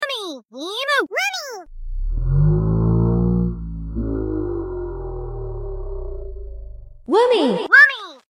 Meggy spletzer Woomy sound effect sound effects free download